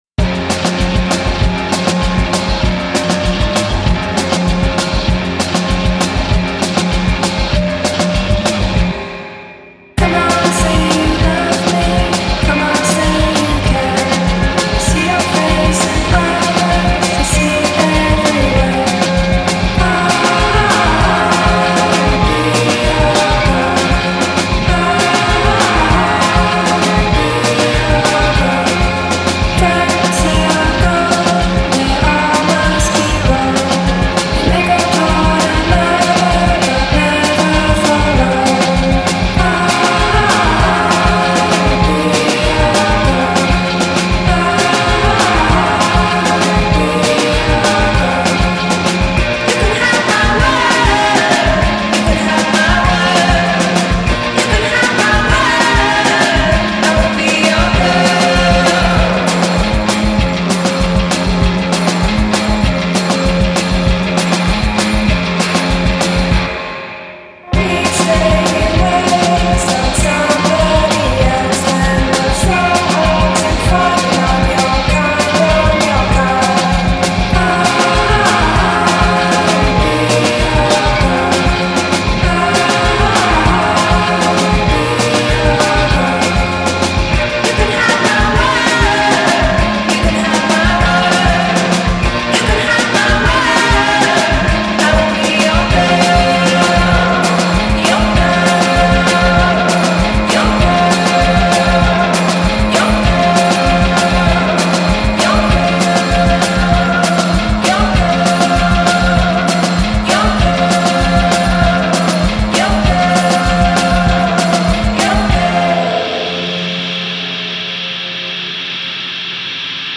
mountains upon mountains of fuzzy distortion